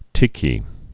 (tēkē)